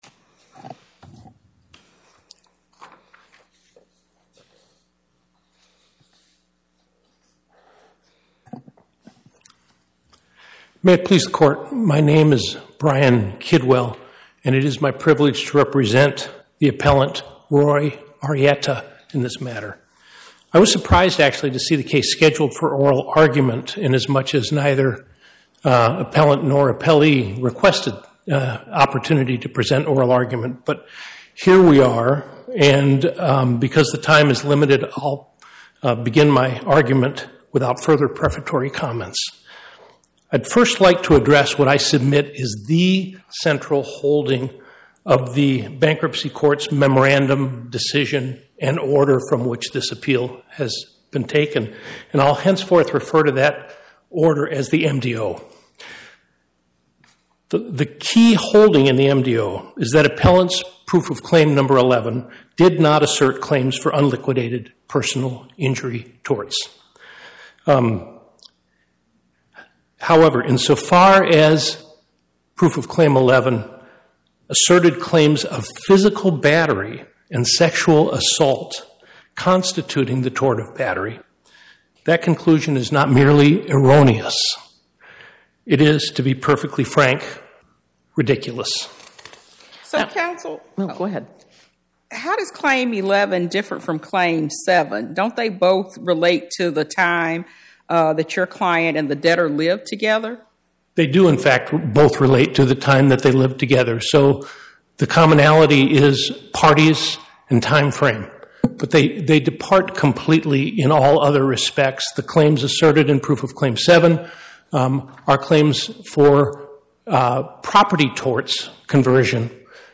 Oral argument argued before the Eighth Circuit U.S. Court of Appeals on or about 08/26/2025